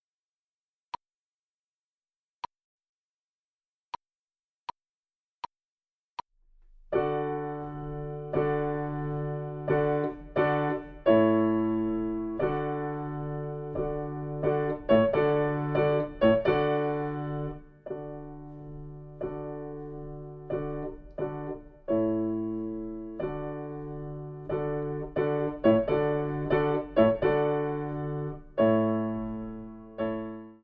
noty pro zobcovou flétnu
Sopran-Blockflöte und Klavier (Gitarre)